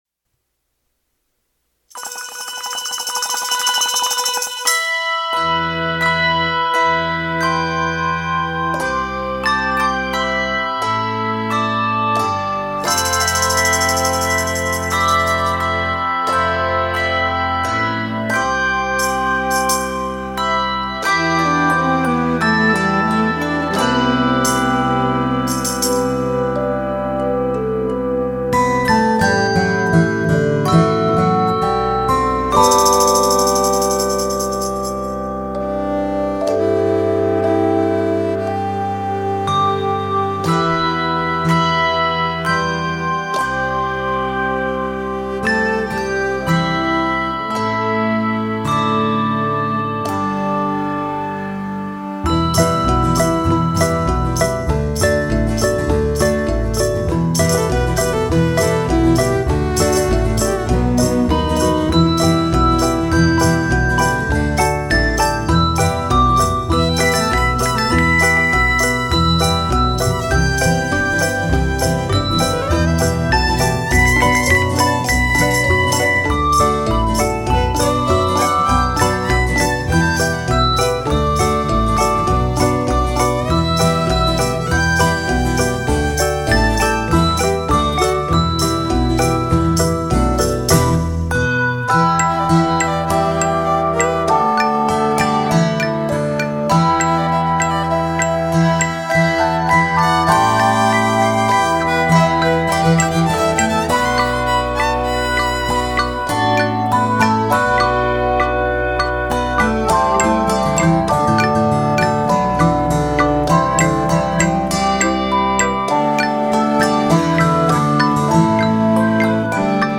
handbells